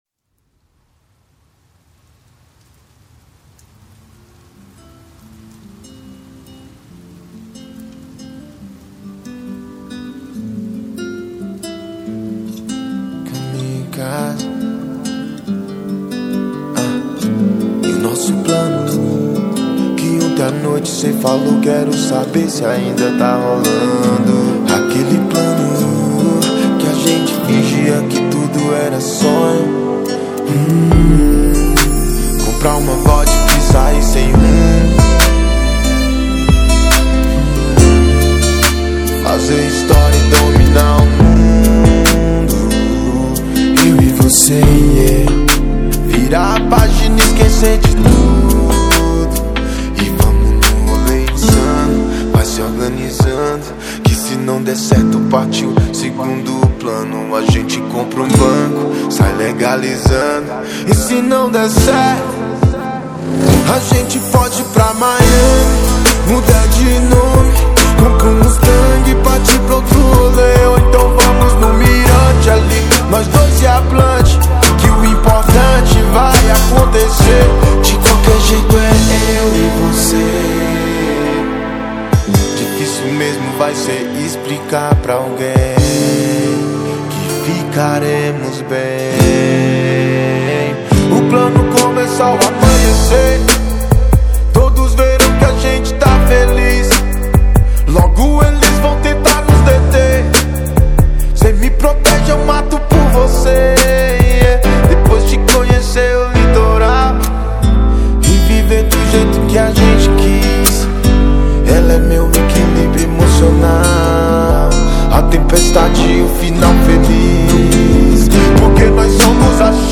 2025-01-27 22:15:39 Gênero: MPB Views